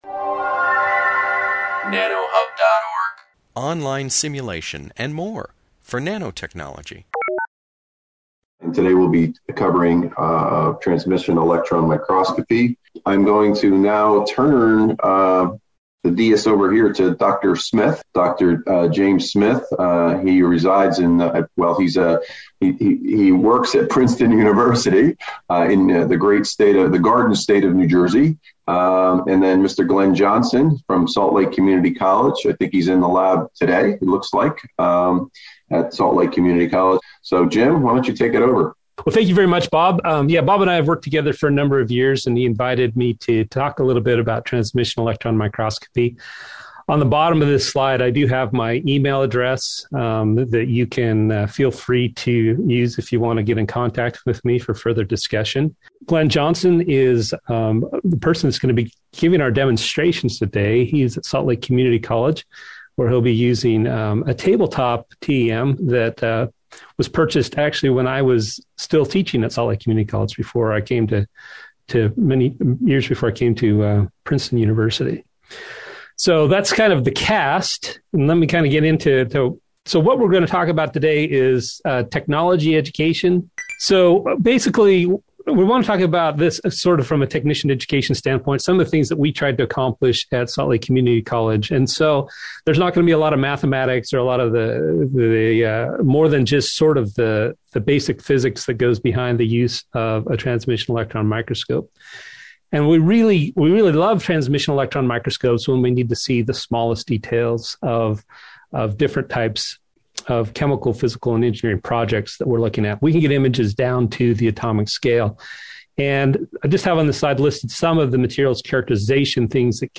This webinar, published by the Nanotechnology Applications and Career Knowledge Support (NACK) Center at Pennsylvania State University, discusses transmission electron microscopy (TEM) and TEM education.